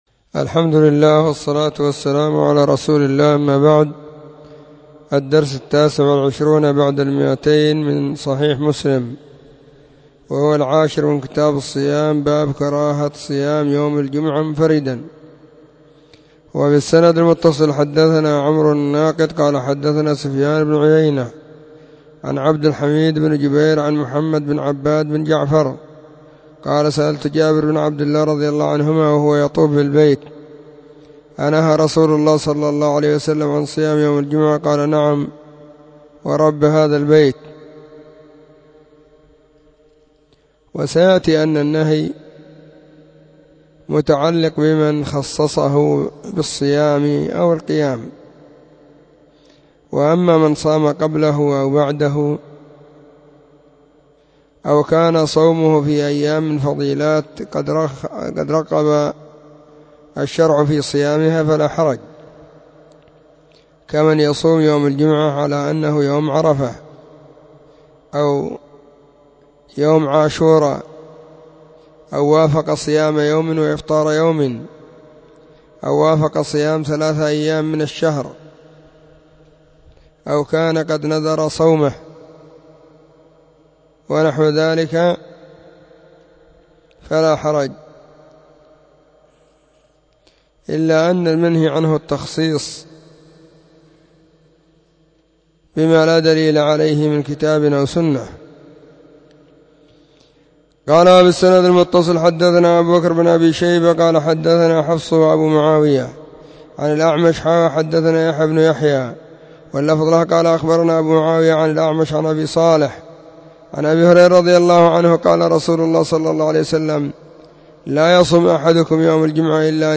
📢 مسجد الصحابة – بالغيضة – المهرة، اليمن حرسها الله.